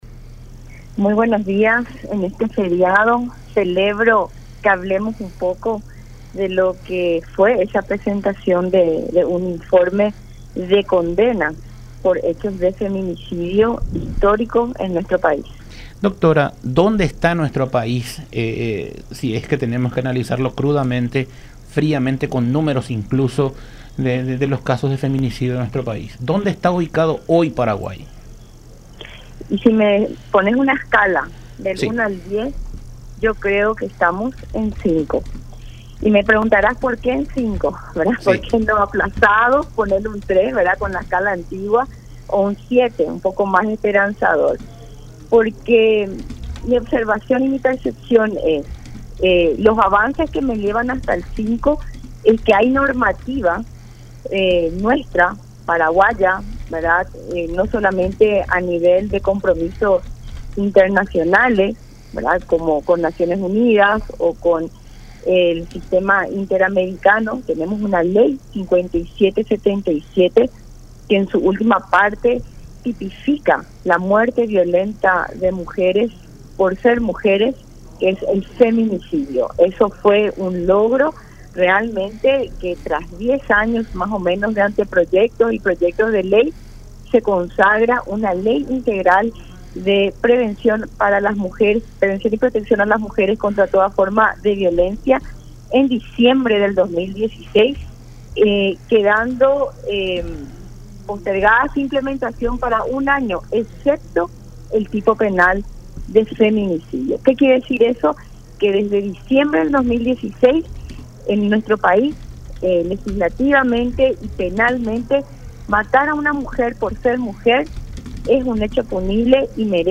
“Falta sensibilización del sistema de justicia. Tenemos un largo camino por recorrer y me hago cargo de lo que digo, porque estuve dentro del sistema de justicia en el Ministerio Público”, expresó Zayas en diálogo con Todas Las Voces por La Unión, en alusión al estudio elaborado por ONU Mujeres y el Programa de las Naciones Unidas para el Desarrollo (PNUD), que reveló que, de 92 casos abiertos por feminicidios, solo 22 cuentan con sentencias firmes.